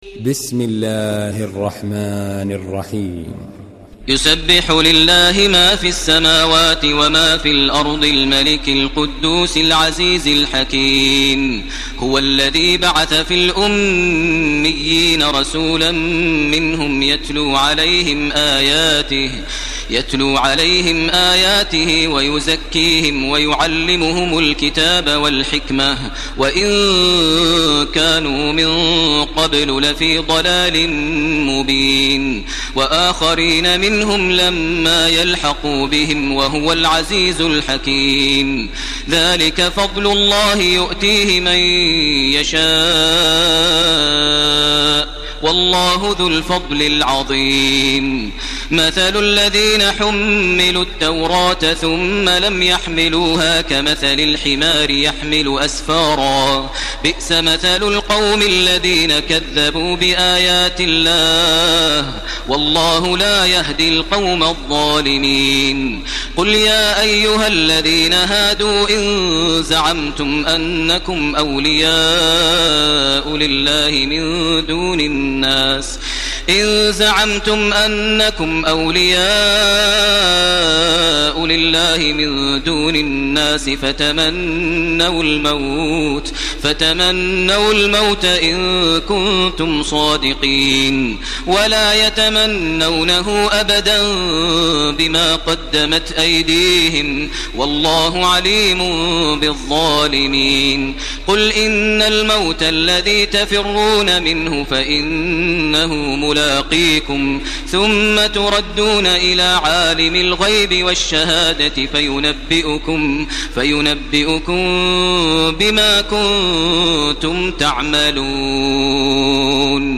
Surah الجمعه MP3 by تراويح الحرم المكي 1431 in حفص عن عاصم narration.
مرتل حفص عن عاصم